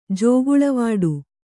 ♪ jōguḷavāḍu